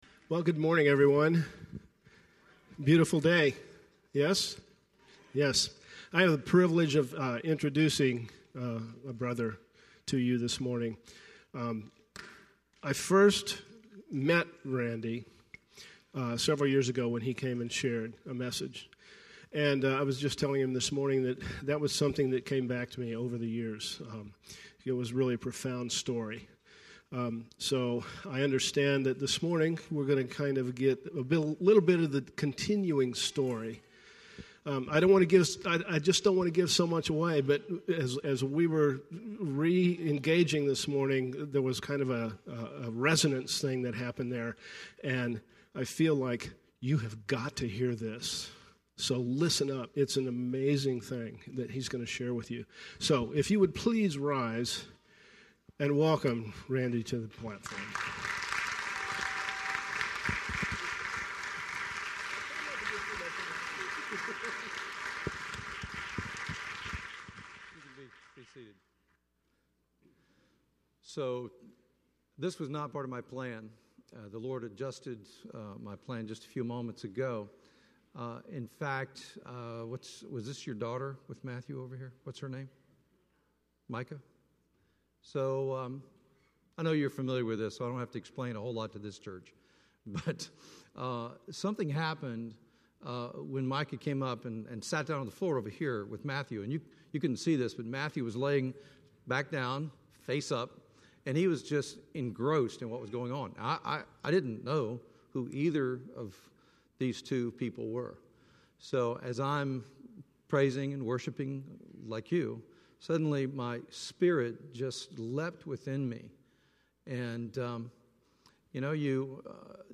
Visiting speaker